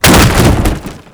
crateBreak4.ogg